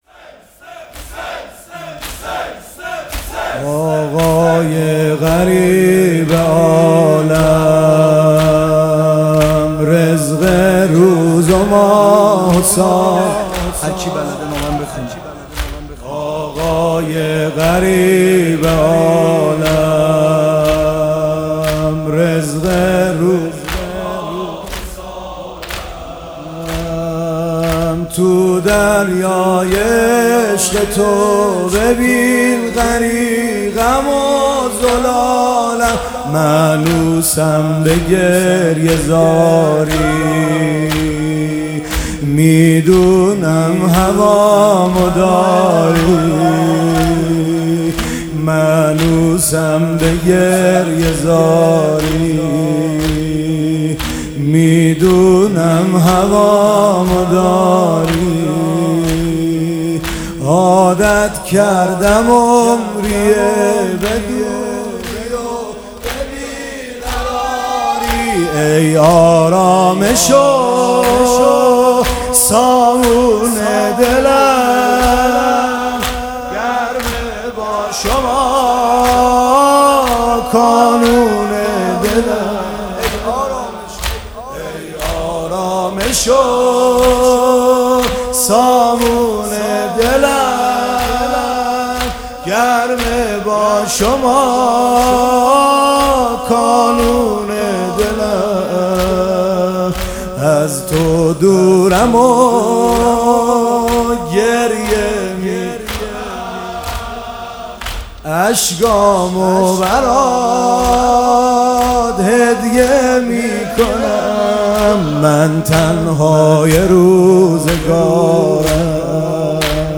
این زمینه برای من یادگار اربعین است ...